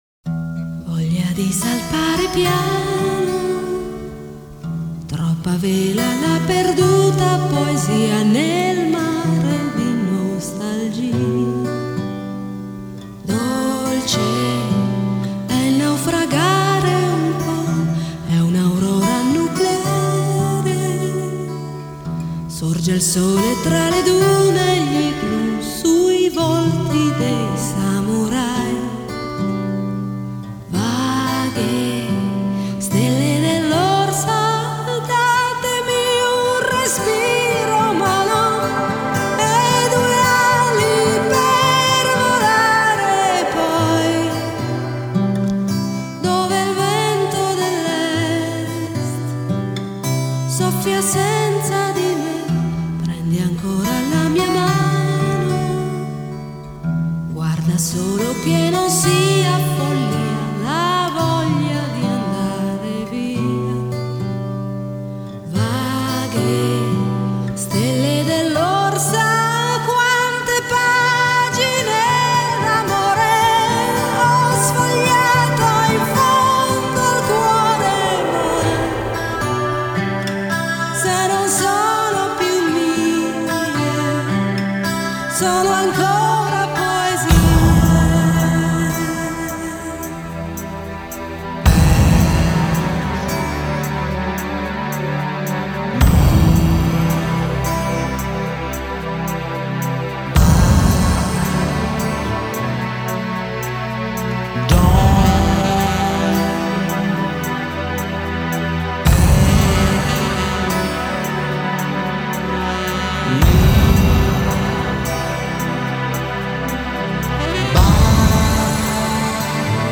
Жанр: Pop, Europop,Vocal,Shlager